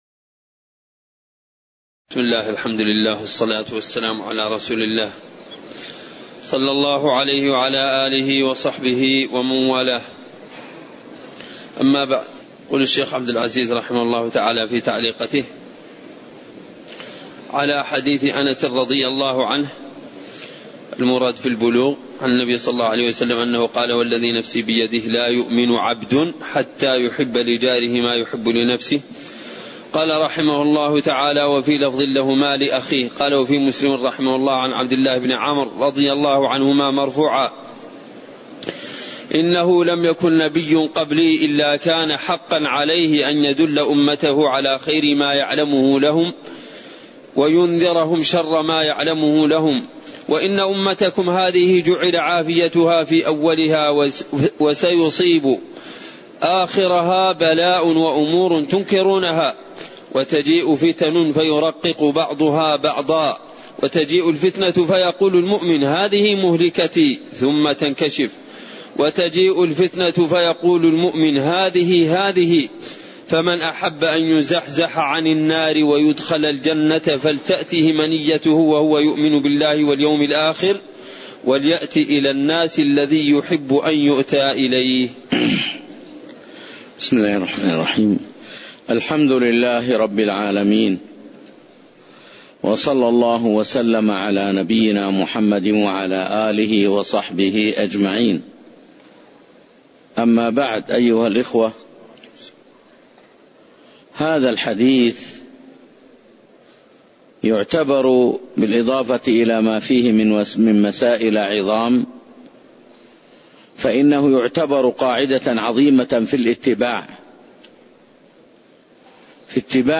تاريخ النشر ٢٧ رجب ١٤٣١ هـ المكان: المسجد النبوي الشيخ